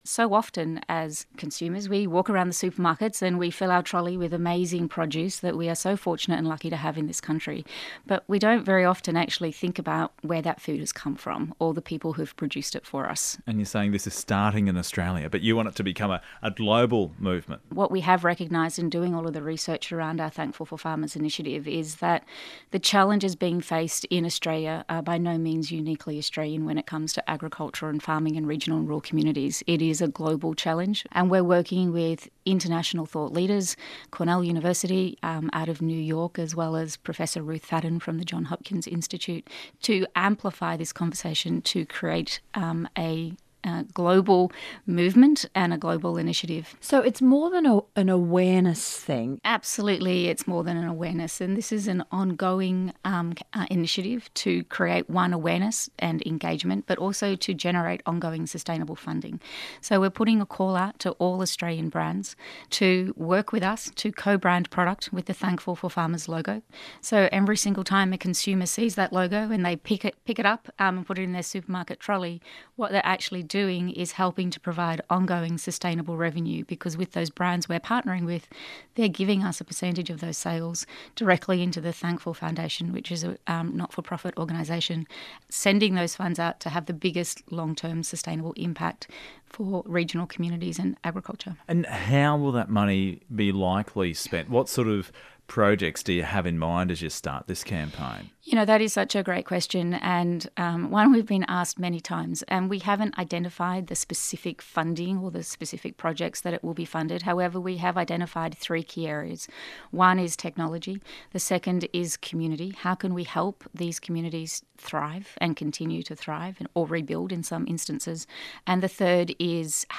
ABC Radio Brisbane interview